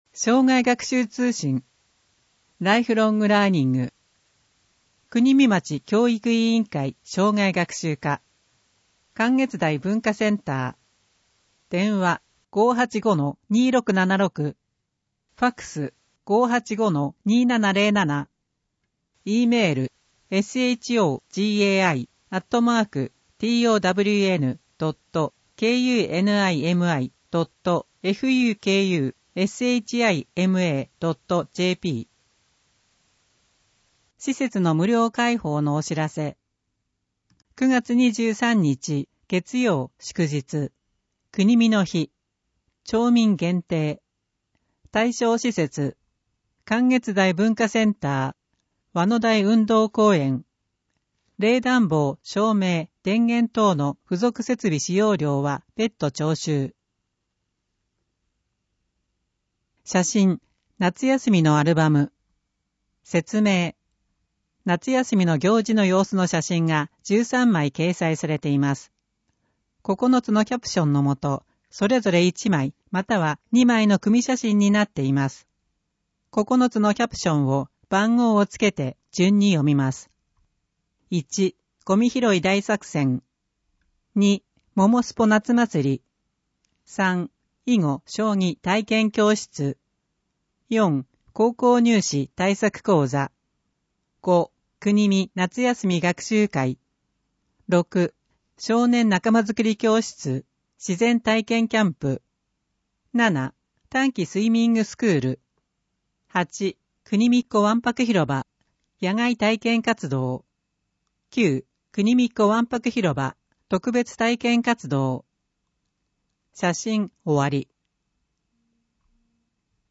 ＜外部リンク＞ 声の広報 声の広報(1) [その他のファイル／10.83MB] 声の広報(2) [その他のファイル／8.28MB]